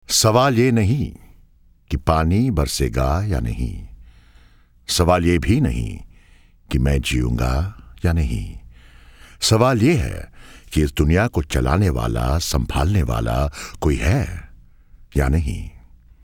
Warm, deep, sexy, up-market.
Sprechprobe: Industrie (Muttersprache):